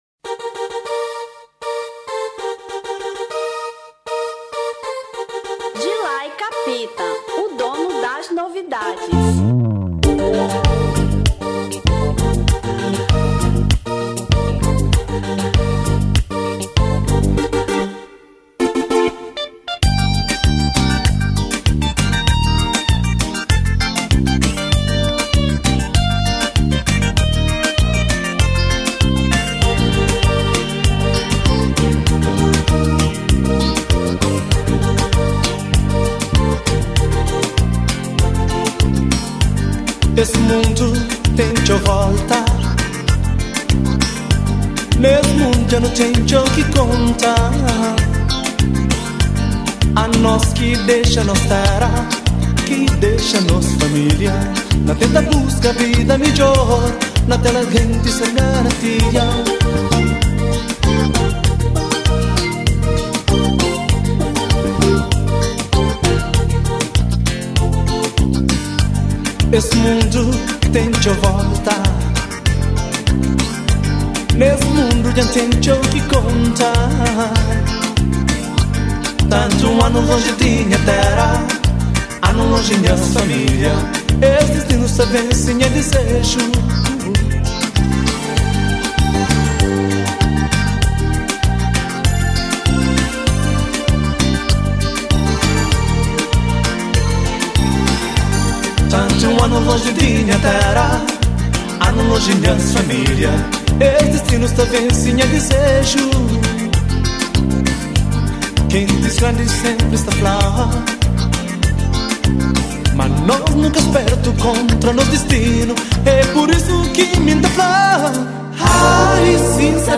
Kizomba 1994